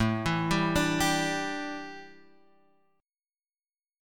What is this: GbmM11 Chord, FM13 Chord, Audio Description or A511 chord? A511 chord